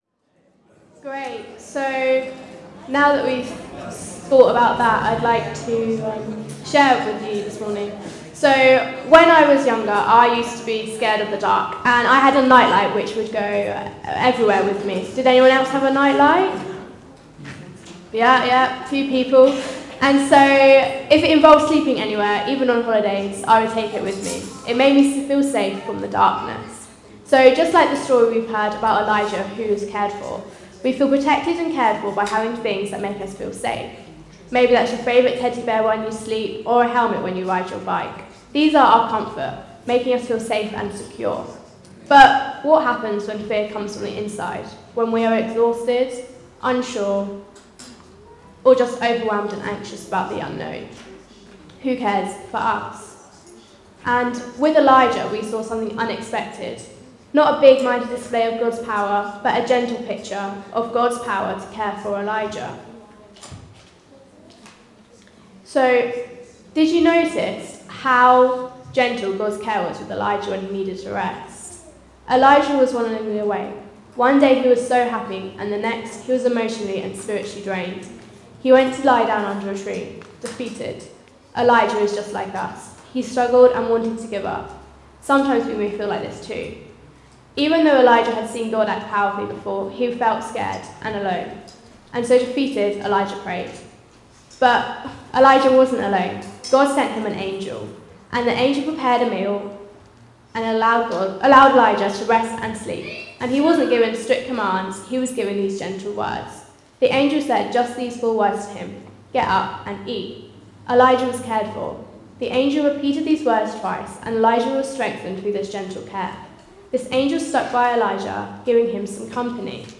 A message from the series "Elijah: Faith and Fire."